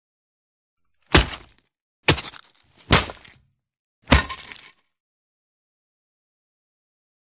Звук ударов томагавком по плоти с хлесткими брызгами крови (это оружие, а не инструмент)